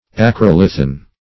Search Result for " acrolithan" : The Collaborative International Dictionary of English v.0.48: Acrolithan \A*crol"i*than\, Acrolithic \Ac`ro*lith"ic\, a. Pertaining to, or like, an acrolith.